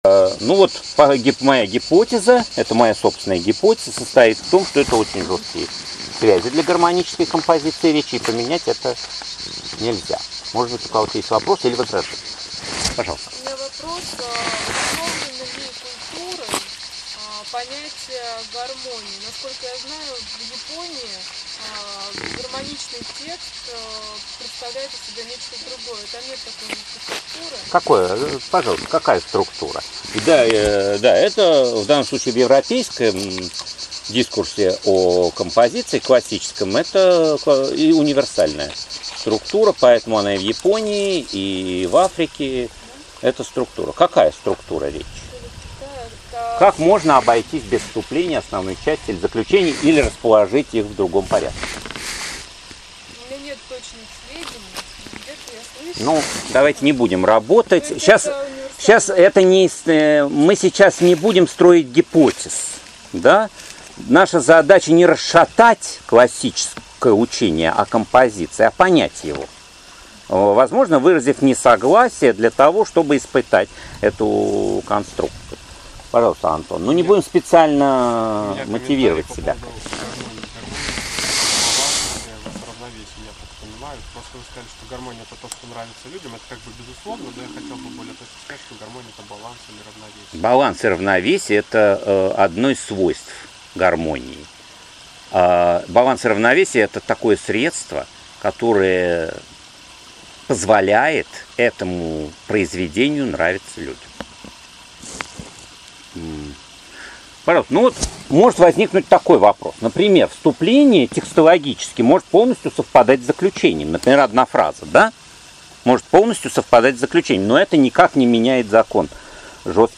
Аудиокнига Композиция: сущность и законы | Библиотека аудиокниг